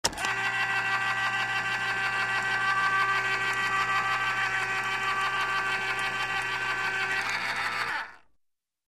Peltitölkin avaaminen sähköavaajalla | äänitehoste .mp3 | Lataa ilmaiseksi.
Peltitölkin avaaminen sähköavaajalla: